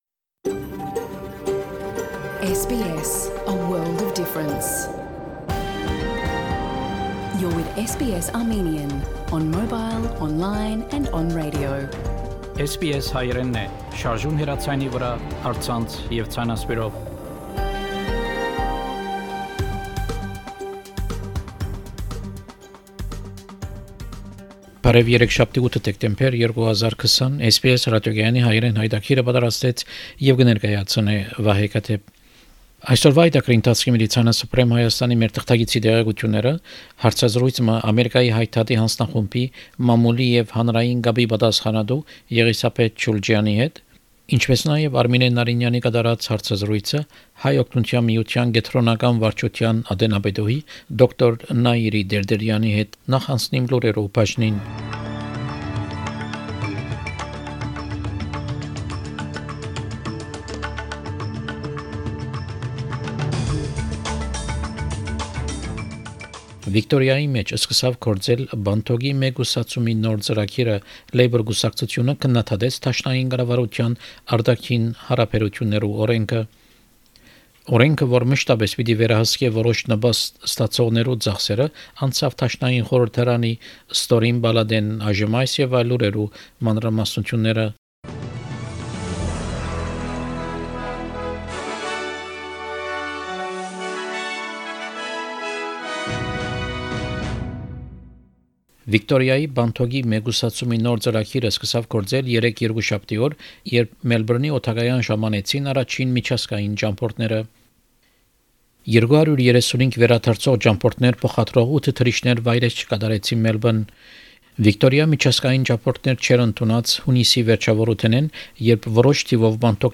SBS Armenian news bulletin – 8 December 2020
SBS Armenian news bulletin from 8 December 2020 program.